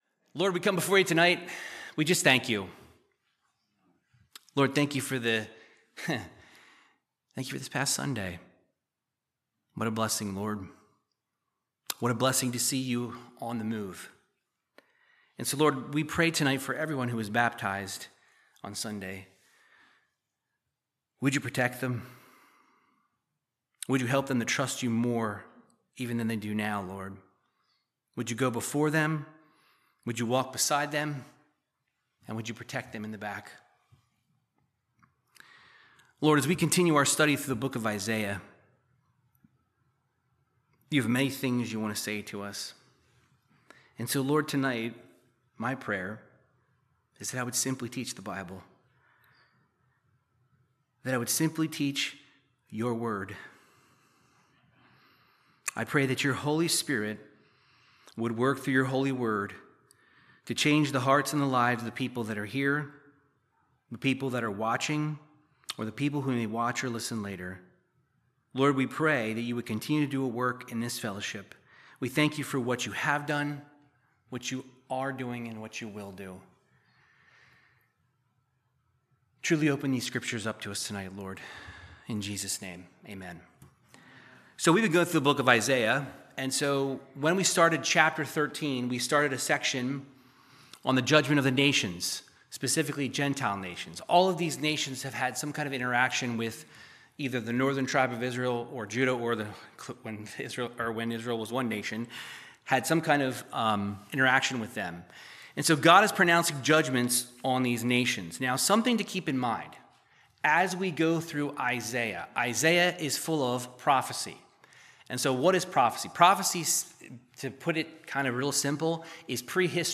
Verse by verse Bible teaching through the book of Isaiah chapters 18 through 20